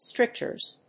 /ˈstrɪktʃɝz(米国英語), ˈstrɪktʃɜ:z(英国英語)/